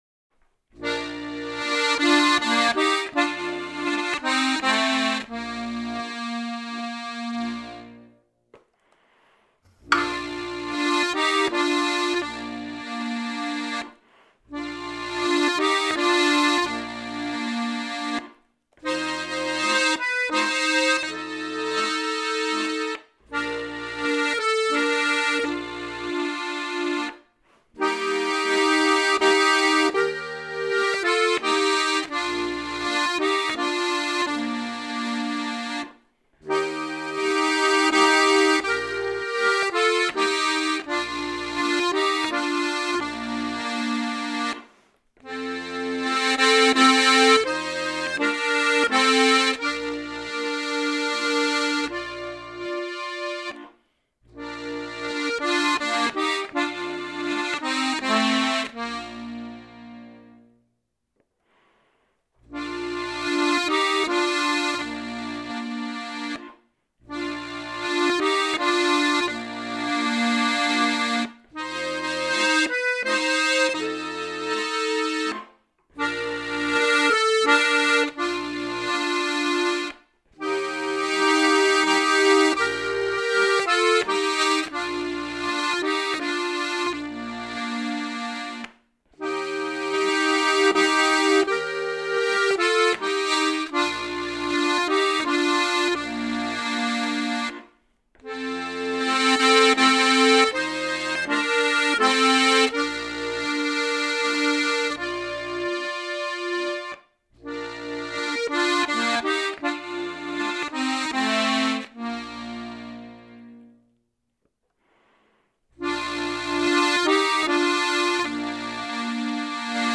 C Dur wie notiert